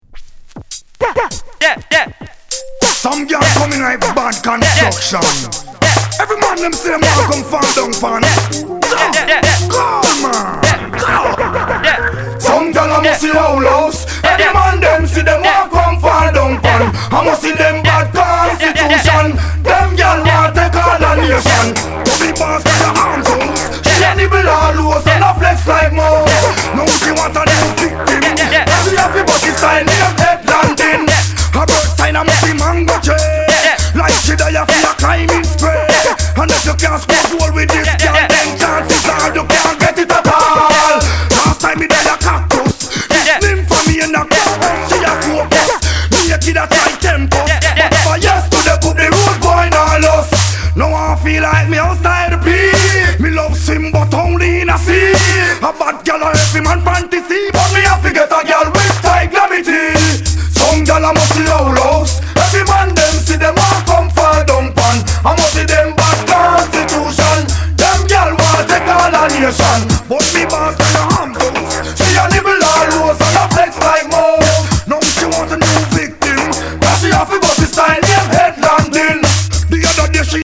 1996 Genre: Reggae / Dancehall Format